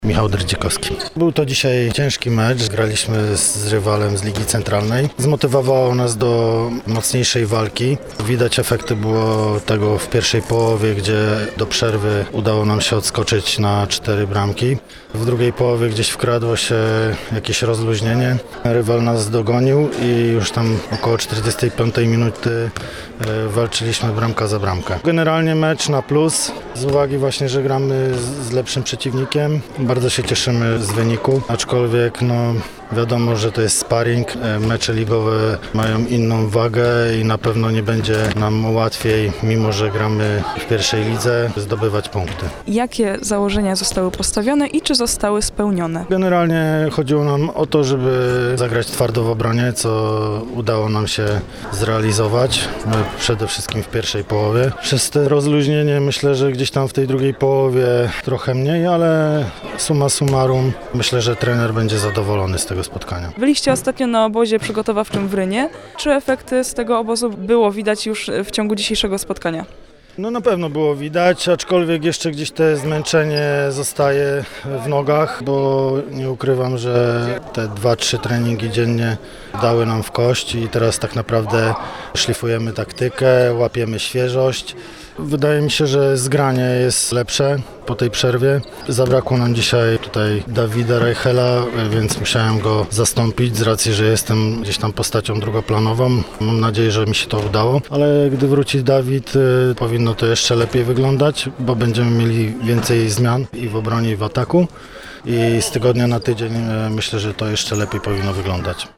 Nasza reporterka rozmawiała także z jednym z zawodników olsztyńskiej drużyny